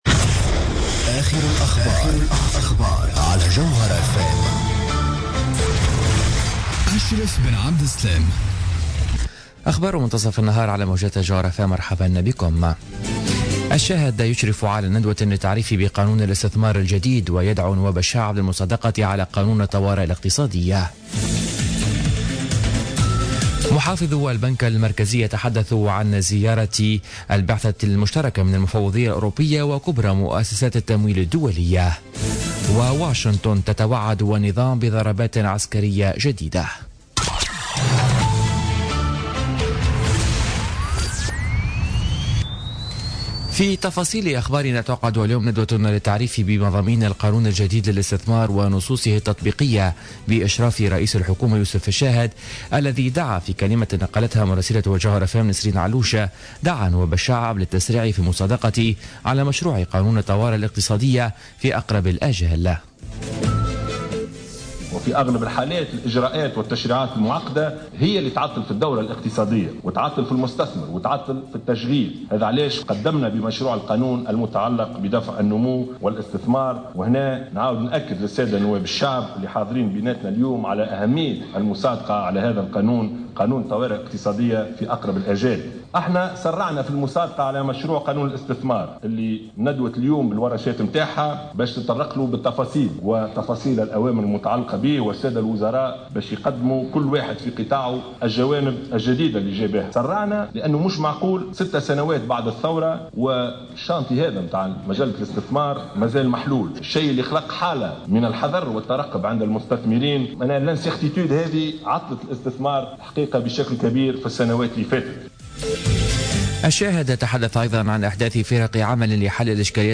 نشرة أخبار منتصف النهار ليوم السبت 8 أفريل 2017